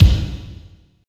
32.07 KICK.wav